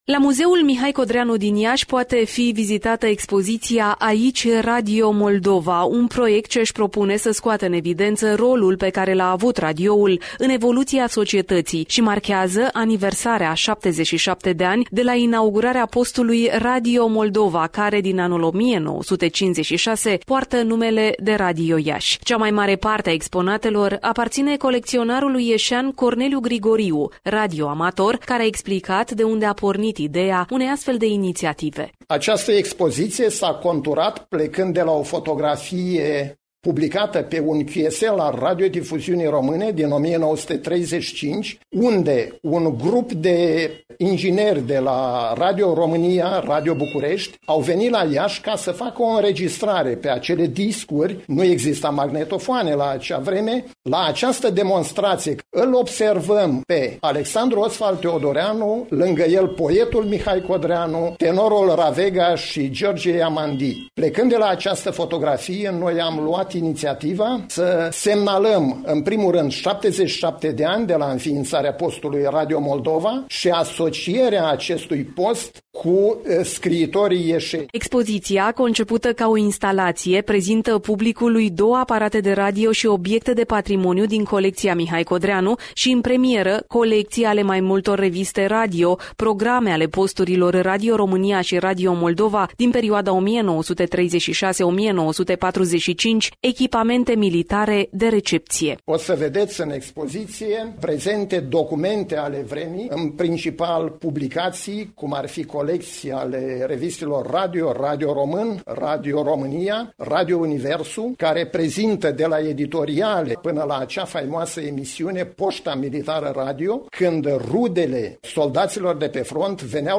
Un radioreportaj